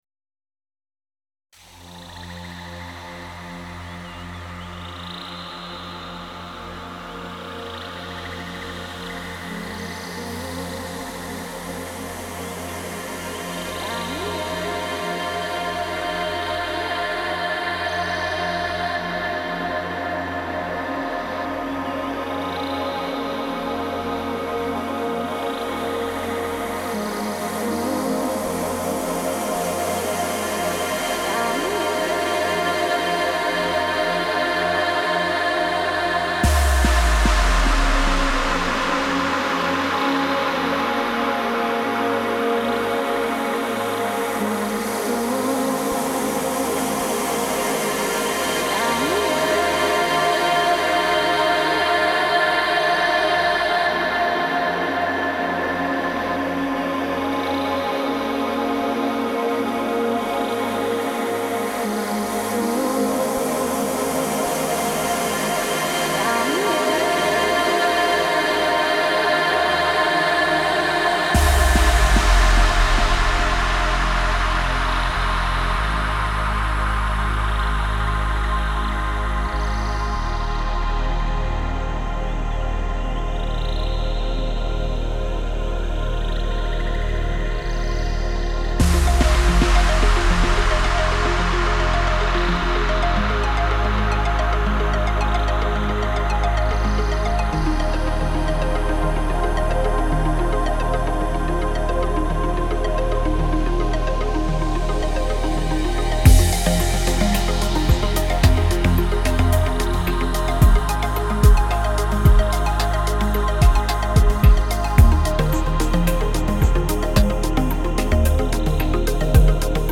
Downtempo/Chillout, Ambient Genre